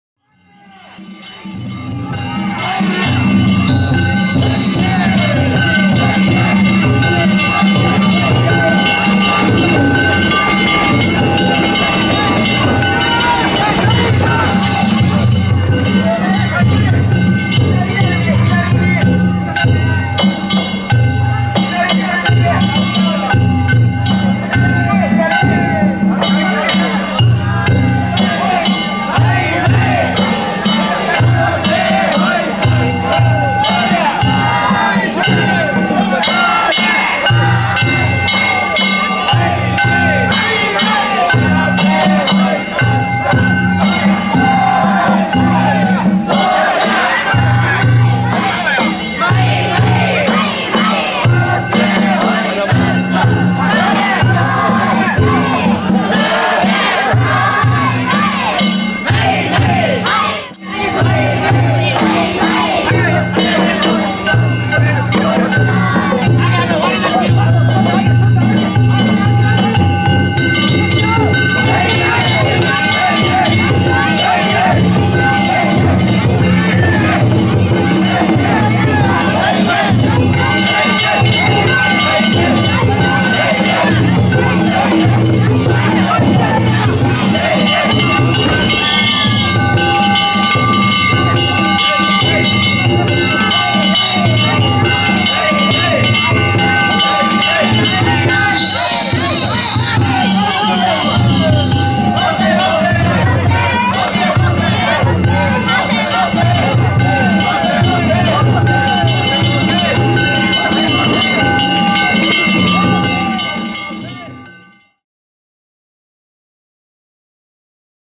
平野郷夏祭り試験曳き
ここでは午後9時半過ぎに宮前で見た泥堂町の宮入練習画像を紹介します。
お囃子はマイマイに変わりました。
お囃子が速くなり、回転も速くなってきました。